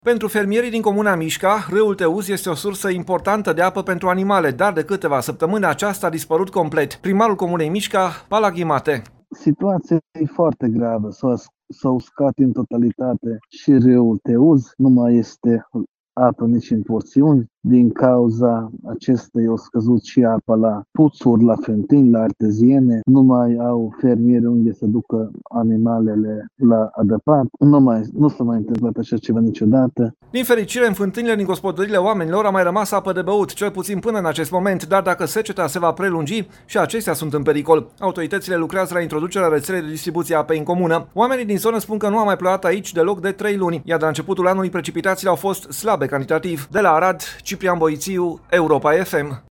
Primarul Comunei Mișca, Pallagi Mate: „Nu s-a mai întâmplat așa ceva niciodată”